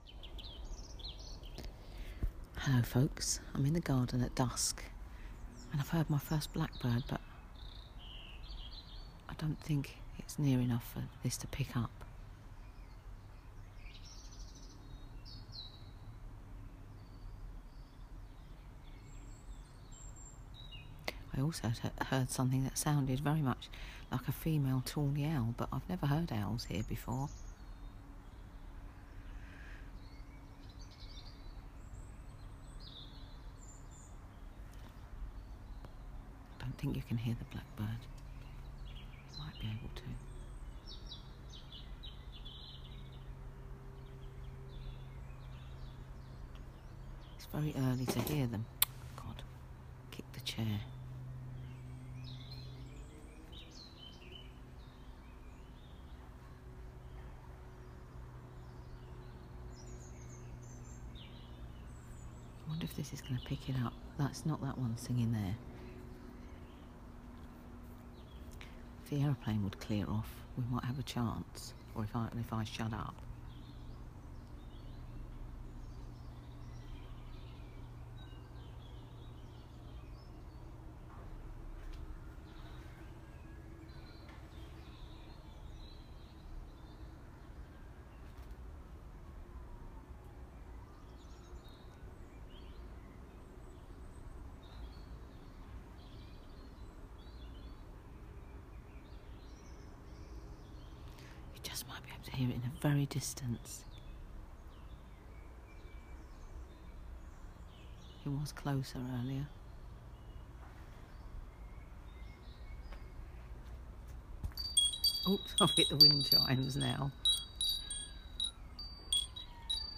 First distant Blackbird at dusk, Friday 17 February 2017